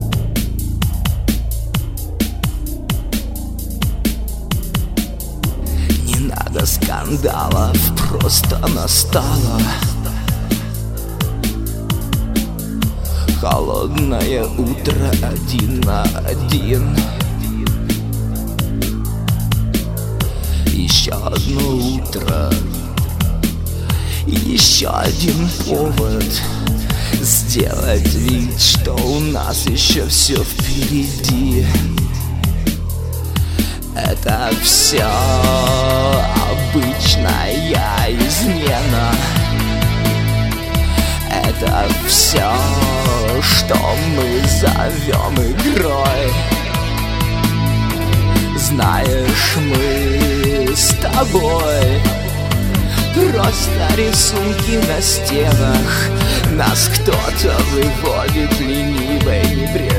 Рок
А теперь вышел и студийный альбом!